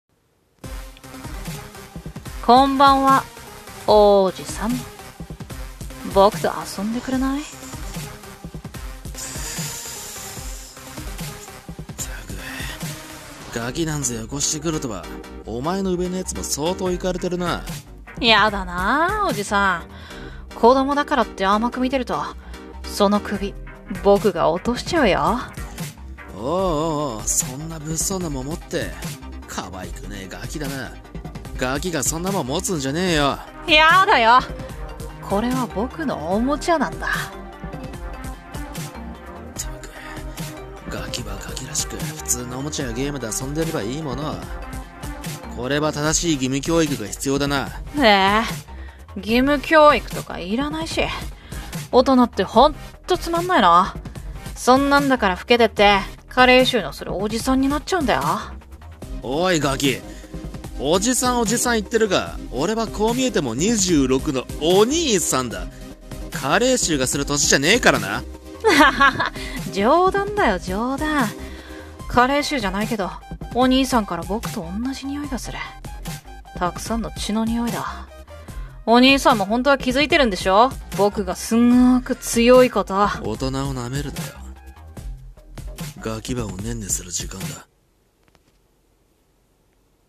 【声劇】同じ匂い【掛け合い】